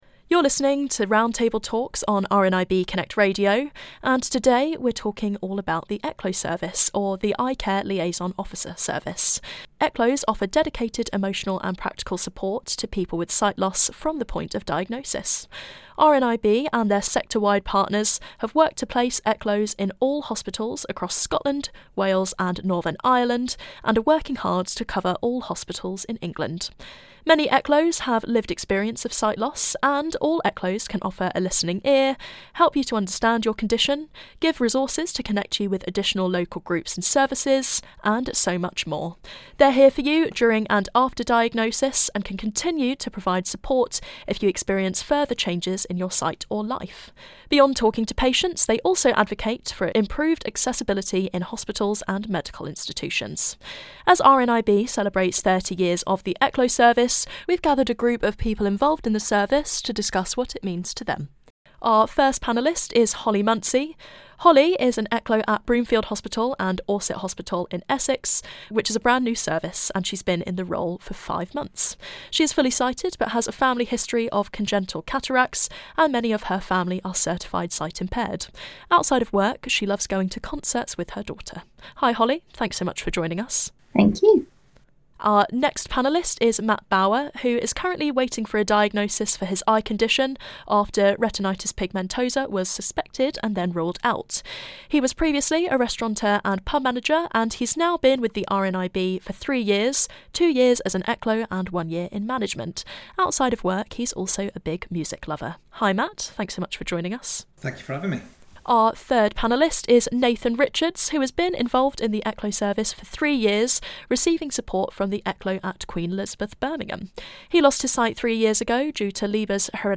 30 Years of RNIB ECLOs - Roundtable
To celebrate RNIB Connect Radio hosted an anniversary roundtable featuring people involved in delivering the service.